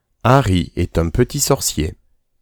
Les dictées du groupes CP :